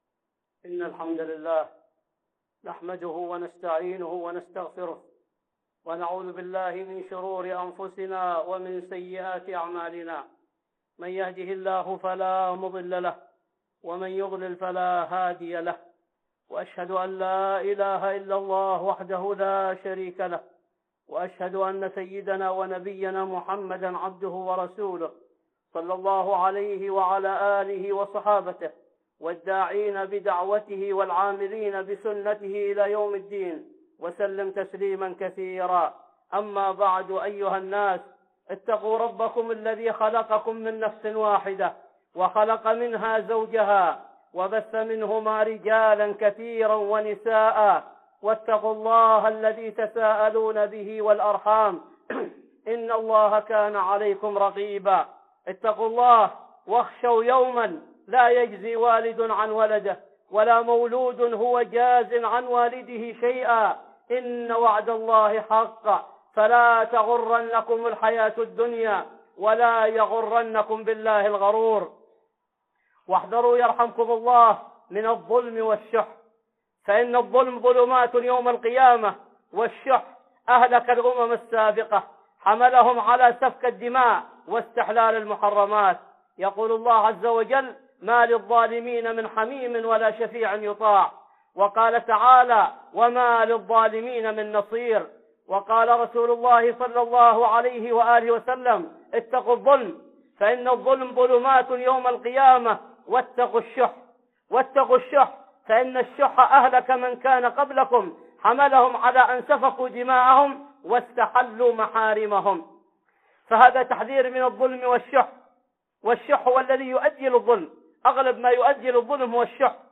(خطبة جمعة) الظلم والشح